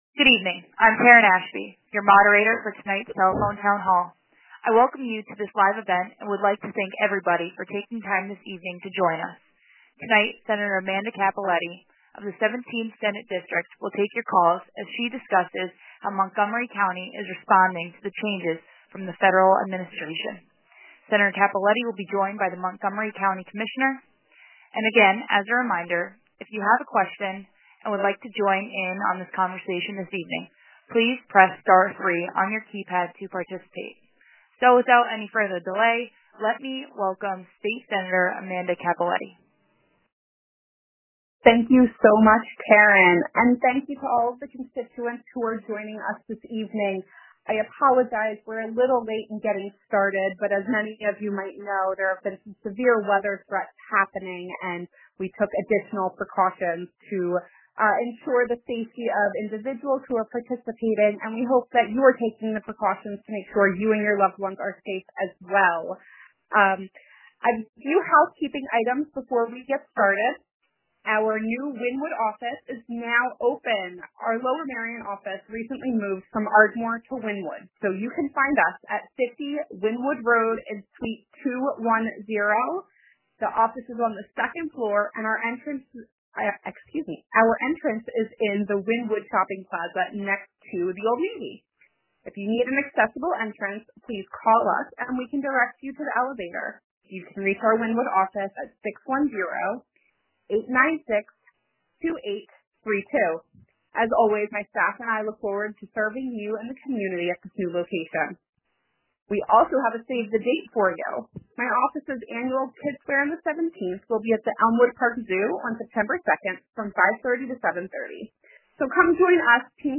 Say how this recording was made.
Telephone Town Hall